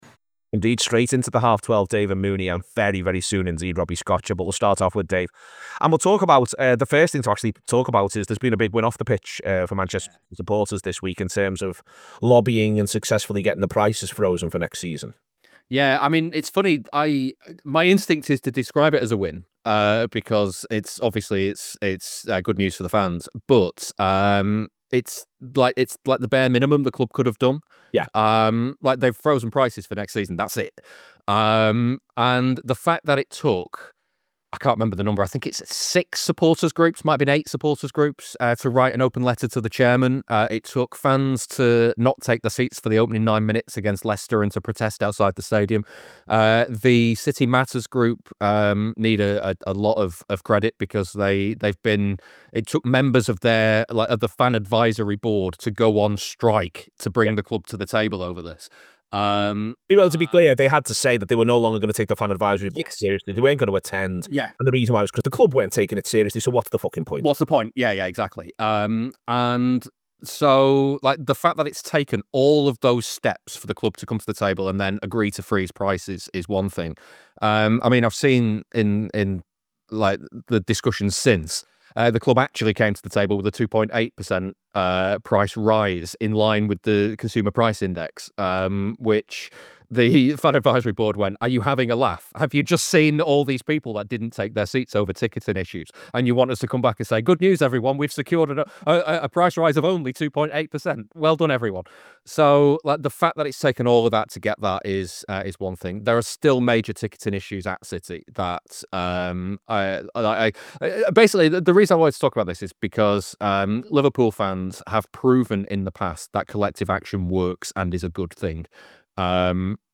The Anfield Wrap’s preview show looking towards the weekends fixtures.